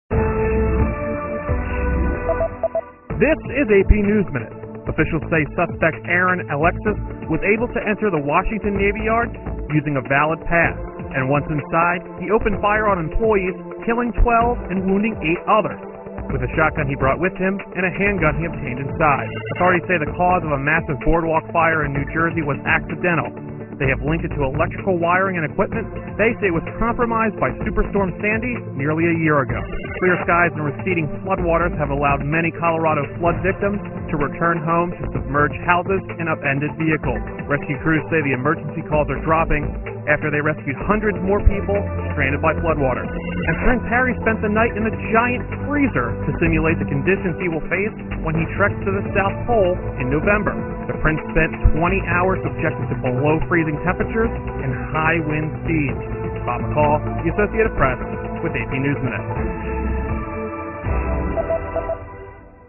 在线英语听力室美联社新闻一分钟 AP 2013-09-24的听力文件下载,美联社新闻一分钟2013,英语听力,英语新闻,英语MP3 由美联社编辑的一分钟国际电视新闻，报道每天发生的重大国际事件。电视新闻片长一分钟，一般包括五个小段，简明扼要，语言规范，便于大家快速了解世界大事。